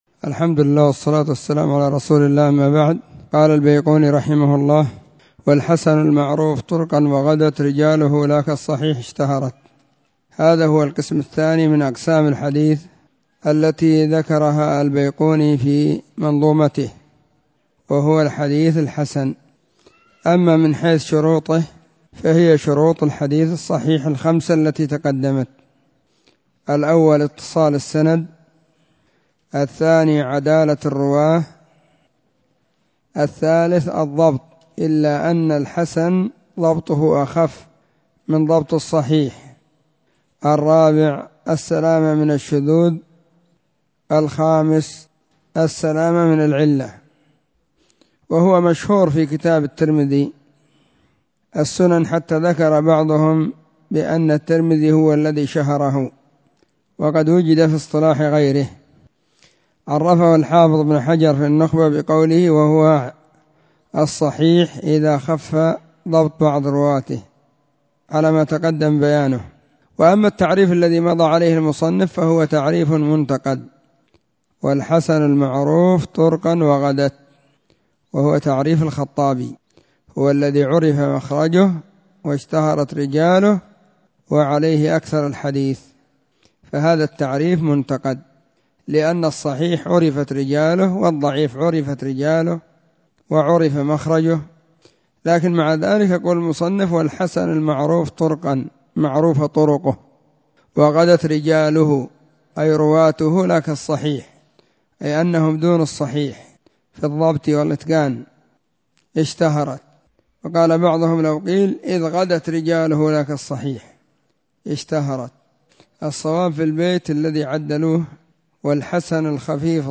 الدرس 3- من التعليق المختصر على المنظومة البيقونية
📢 مسجد الصحابة – بالغيضة – المهرة، اليمن حرسها الله.